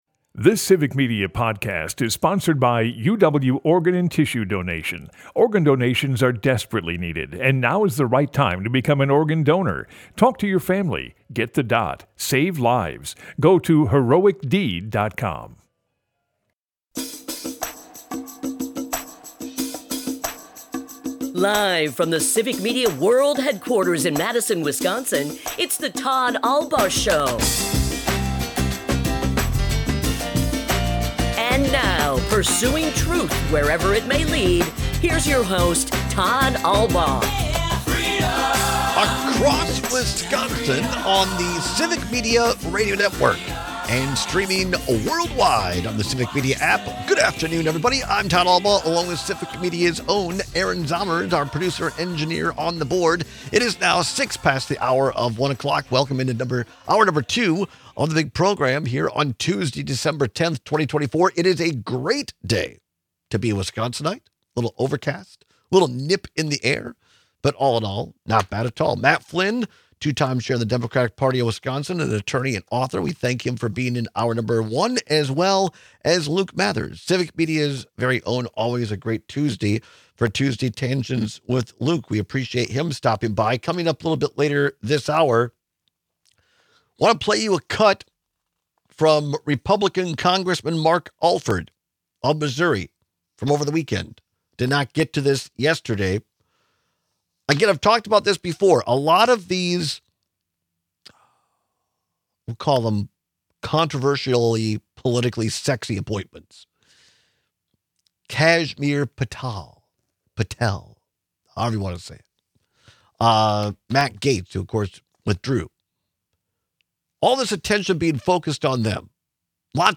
Broadcasts live 12 - 2p across Wisconsin.
We take your calls with crazy car dealer and realtor stories. The controversy surrounding President-elect Trump’s intentions with Medicare and Social Security continues to escalate.